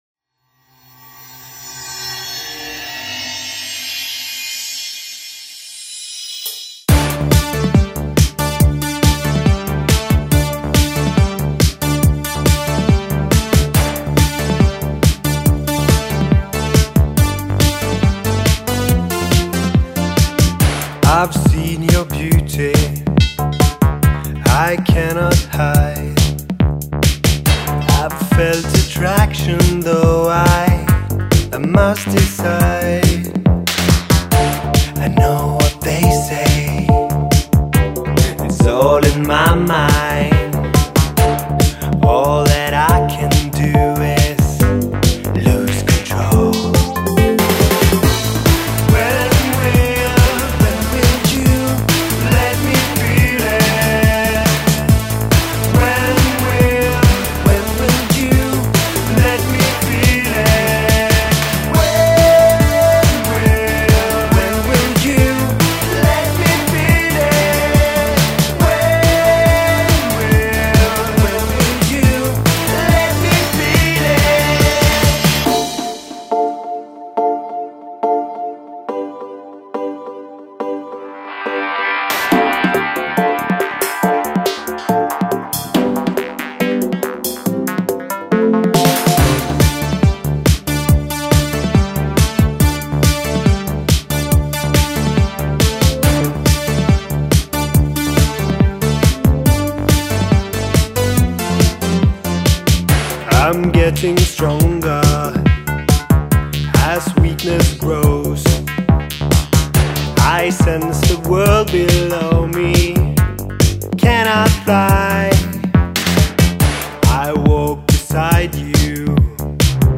Old school synth pop